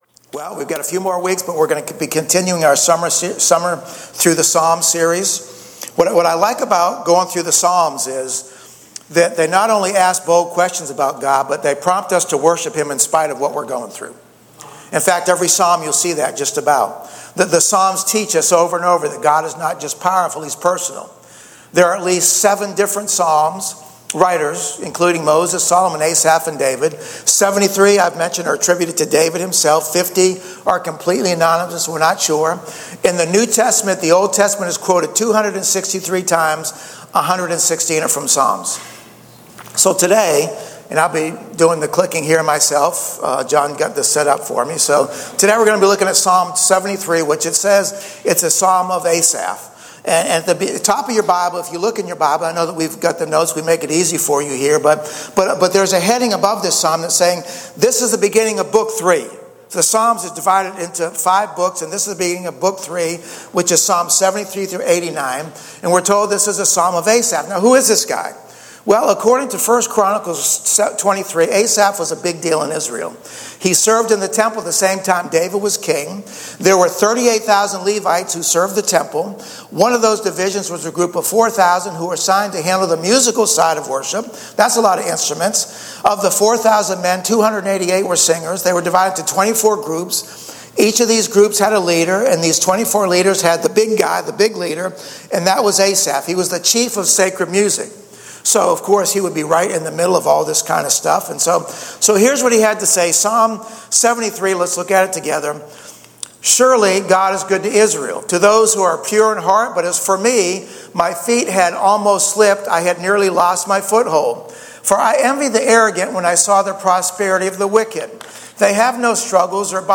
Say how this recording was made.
Online-Church-Aug-18-AUDIO.mp3